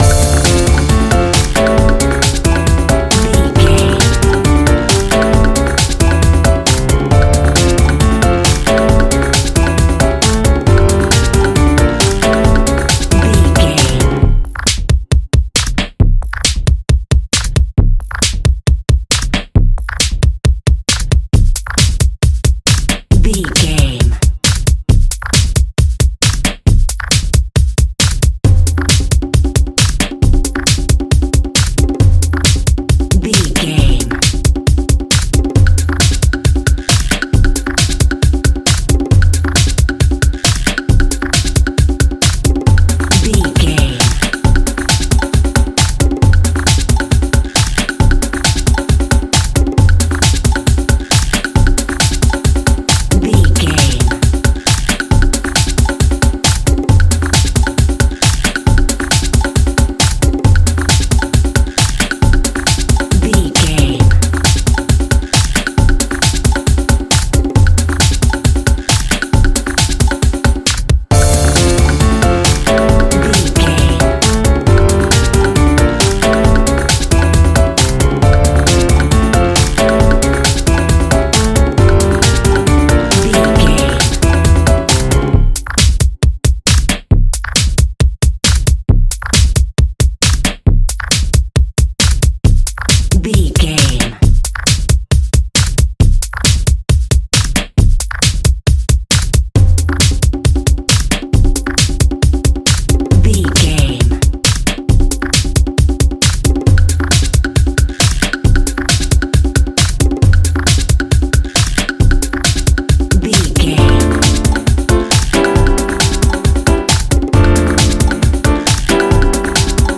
Uplifting
Ionian/Major
flamenco
rumba
percussion
bongos
piano